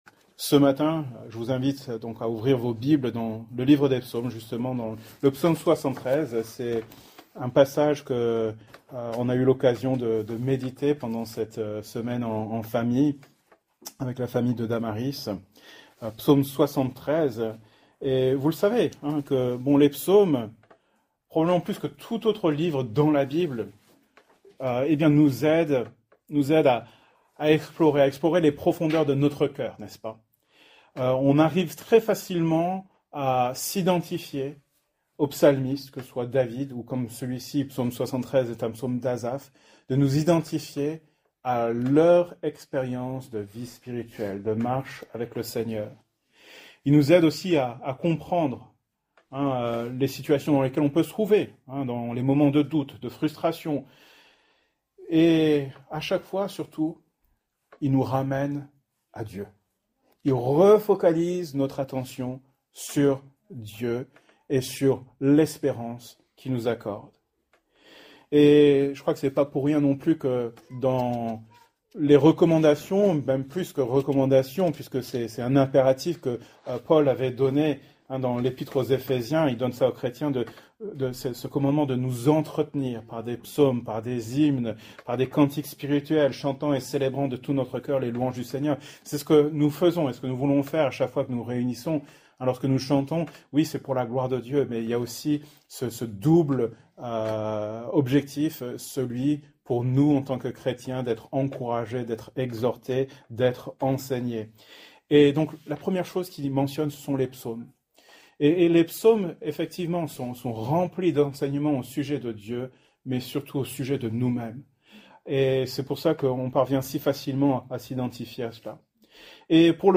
Thème: Bonté de Dieu Genre: Prédication